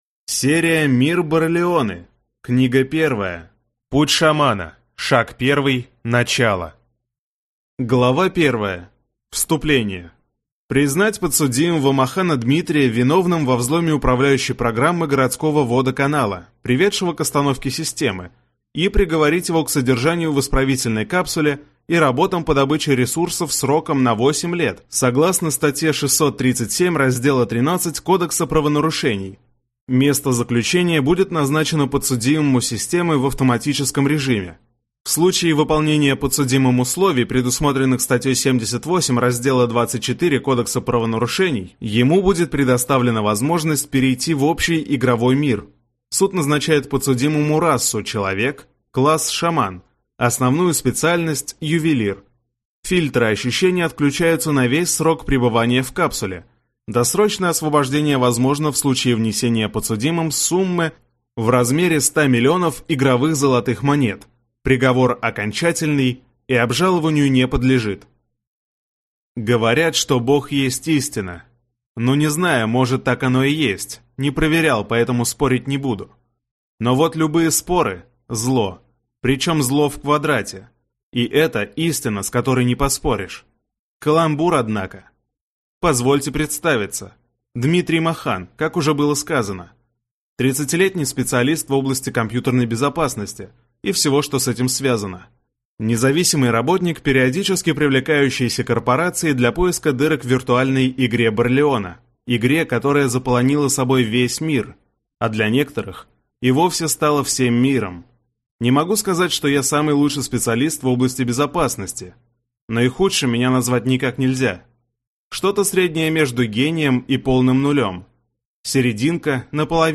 Аудиокнига Путь Шамана. Шаг 1. Начало | Библиотека аудиокниг